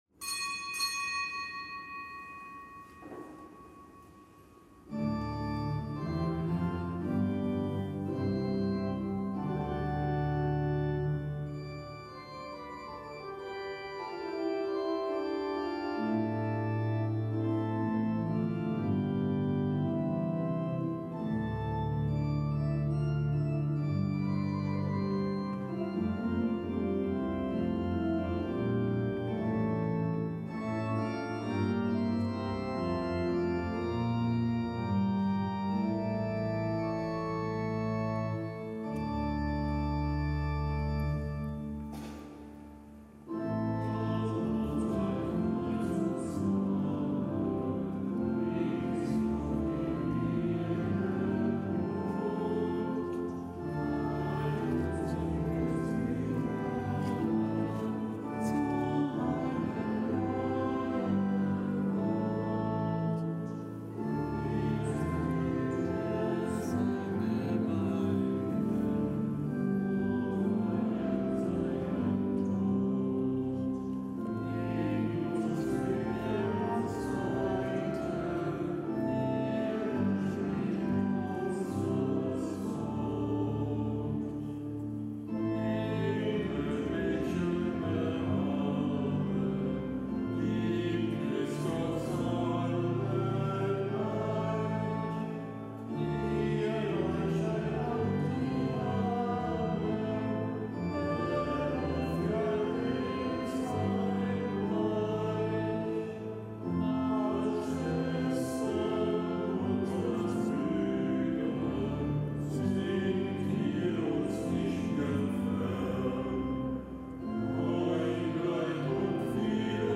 Kapitelsmesse aus dem Kölner Dom zur Eröffnung der Dreikönigswallfahrt am Donnerstag der fünfundzwanzigsten Woche im Jahreskreis; Nichtgebotener Gedenktag Heiliger Niklaus von Flüe, Einsiedler, Fri